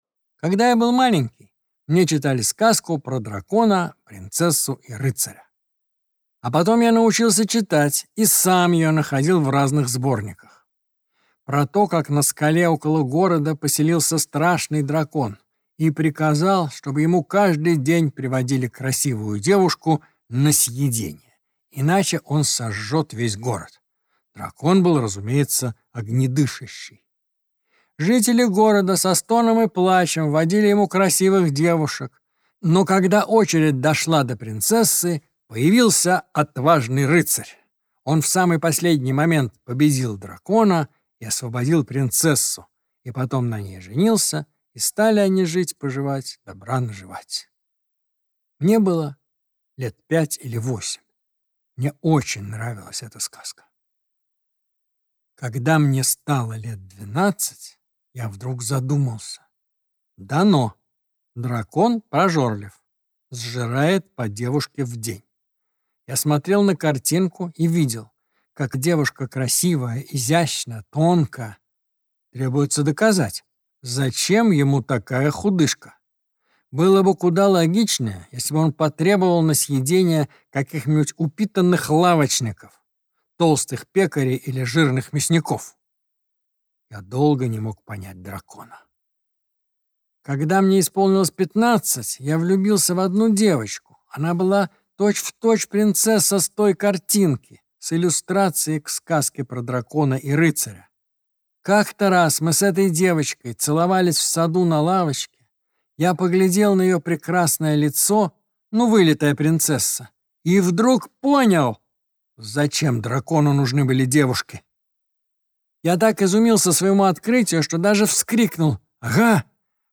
Аудиокнига Обманщики | Библиотека аудиокниг
Прослушать и бесплатно скачать фрагмент аудиокниги